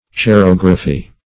Search Result for " cerography" : The Collaborative International Dictionary of English v.0.48: Cerography \Ce*rog"ra*phy\, n. [Gr. khro`s wax + -graphy.]